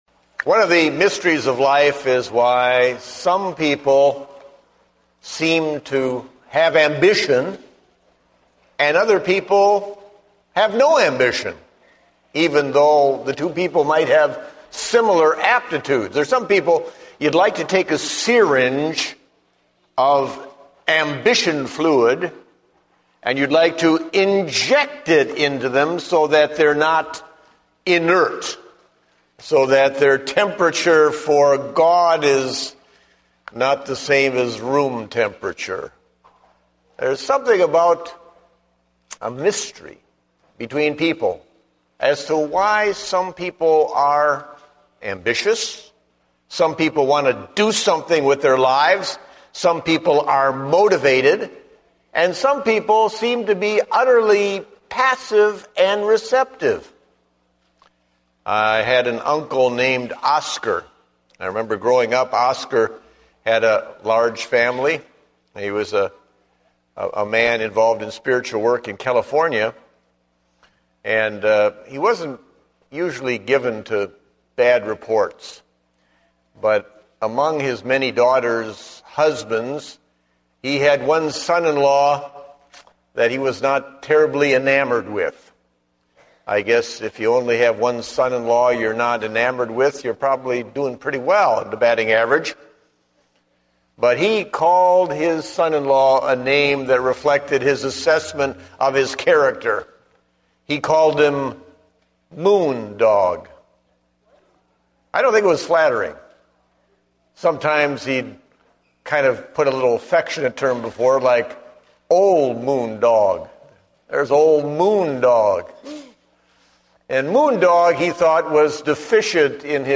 Date: September 19, 2010 (Morning Service)